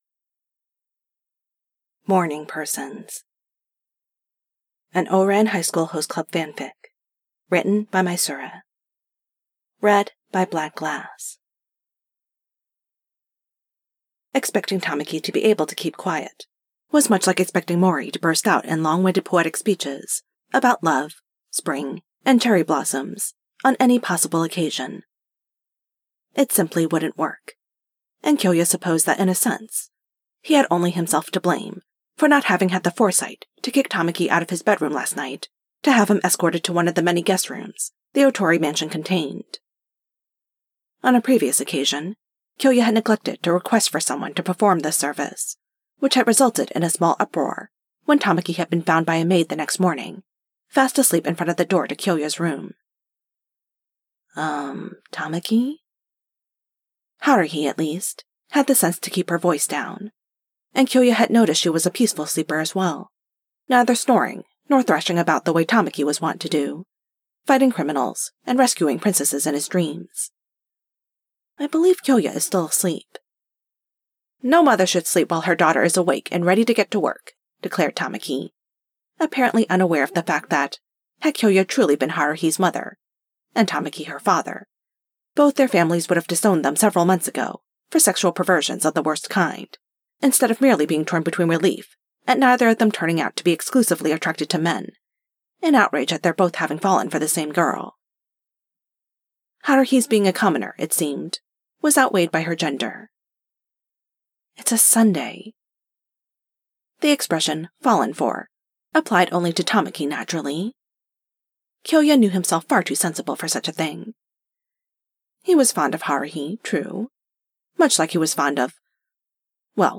A podfic of Morning Persons by misura.